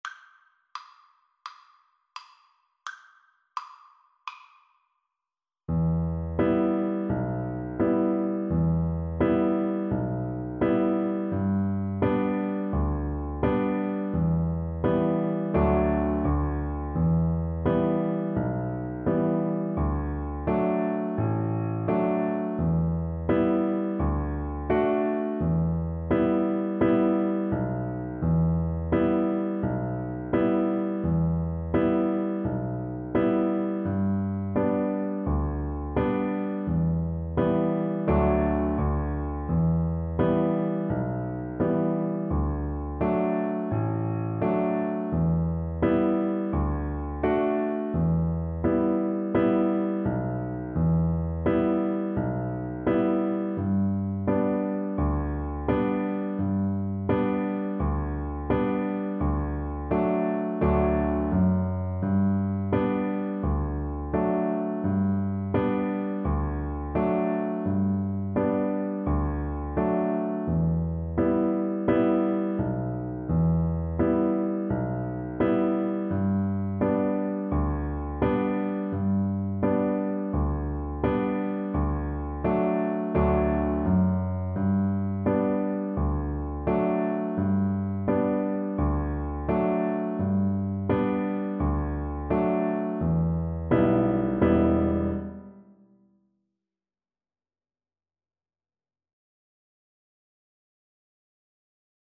Play (or use space bar on your keyboard) Pause Music Playalong - Piano Accompaniment Playalong Band Accompaniment not yet available transpose reset tempo print settings full screen
Violin
D major (Sounding Pitch) (View more D major Music for Violin )
Allegro (View more music marked Allegro)
4/4 (View more 4/4 Music)
Traditional (View more Traditional Violin Music)